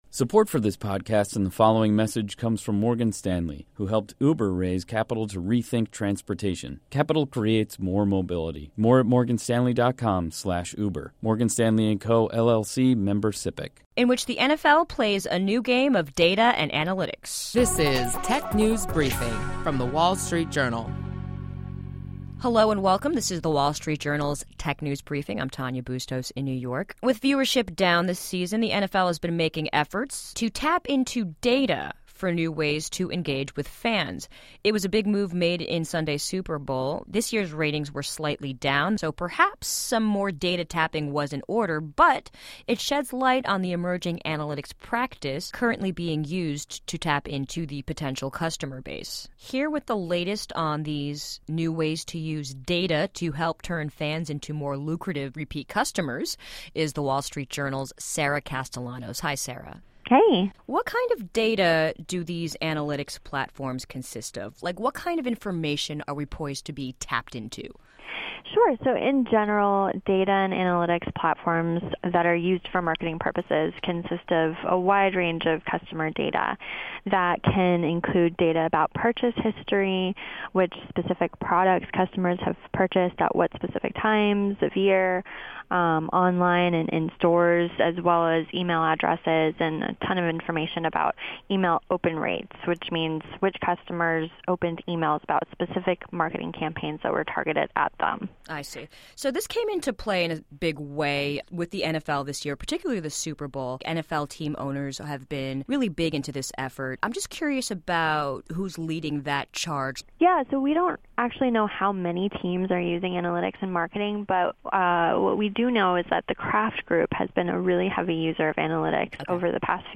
Stay informed on the latest trends with daily insights on what’s hot and happening in the world of technology. Listen to our reporters discuss notable company news, new tech gadgets, personal technology updates, app features, start-up highlights and more.